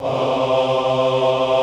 MALE AAH.wav